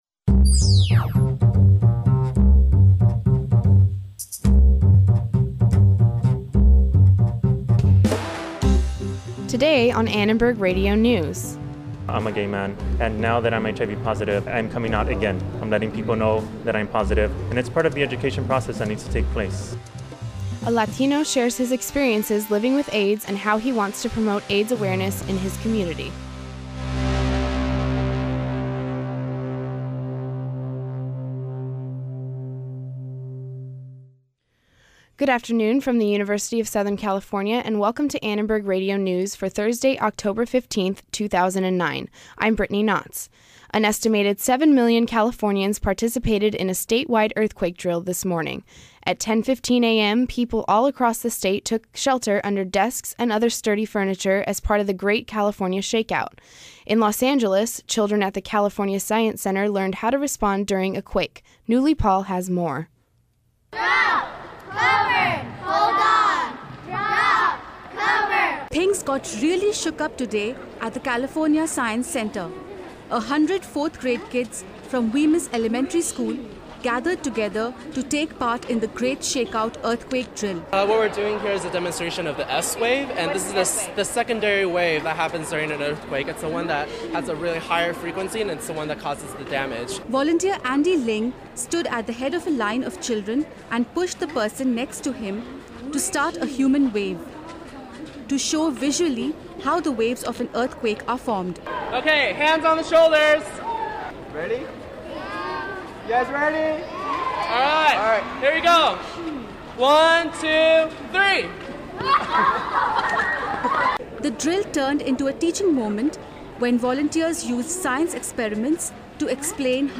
Today was the Great California Shake-Out, a statewide earthquake drill. Listen to how local children and residents participated and learn how to prepare for a real quake.